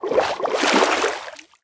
sounds / liquid / swim7.ogg
swim7.ogg